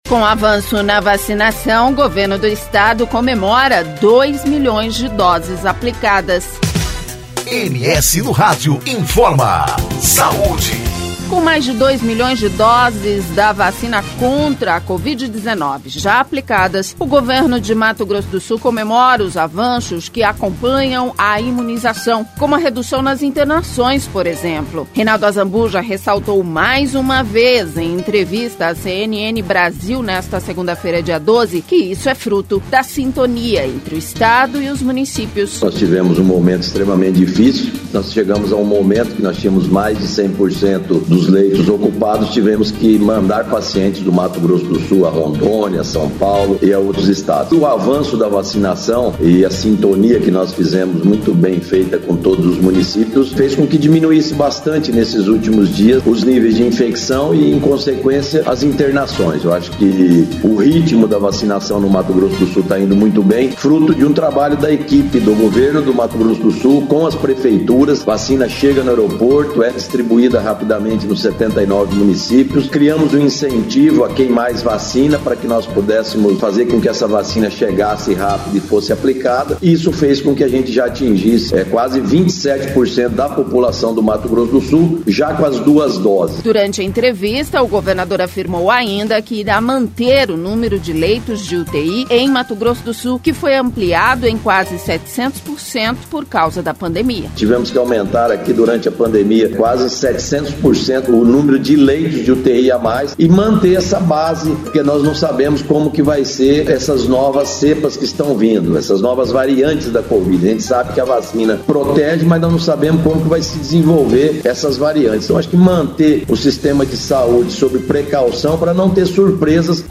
Durante entrevista o governador afirmou ainda que irá manter o número de leitos de UTI em Mato Grosso do Sul, que foi ampliado em quase 700% por causa da pandemia.